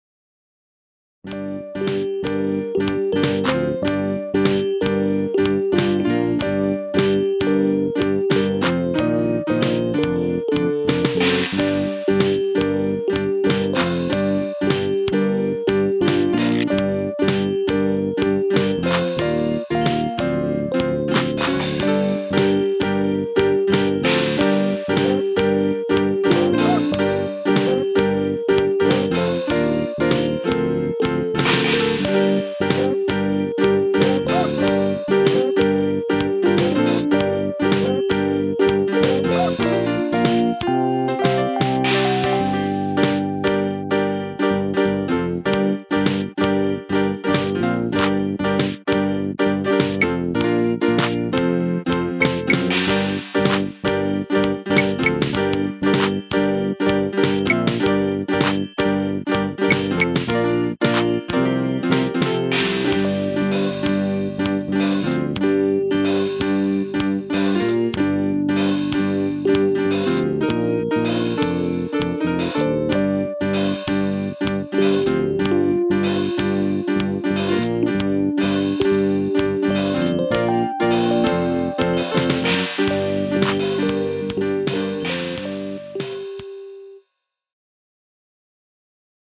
Some Percussion with simple Electric Piano Melody